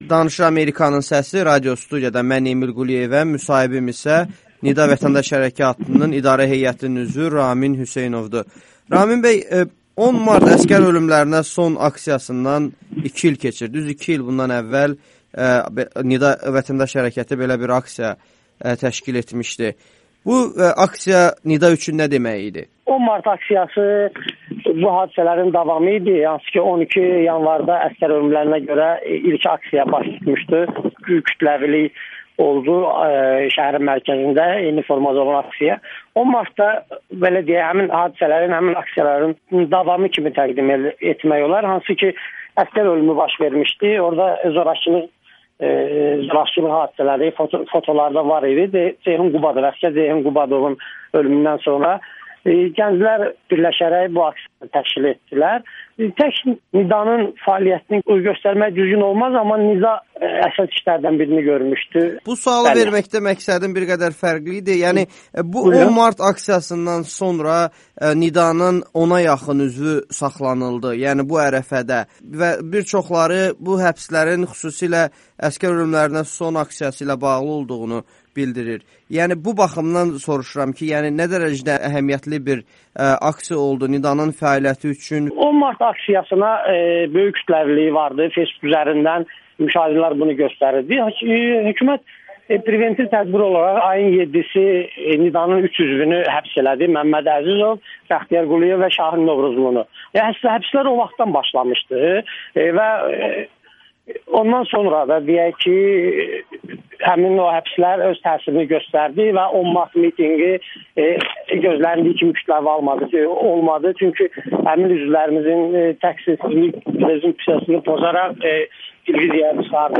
Müsahibələr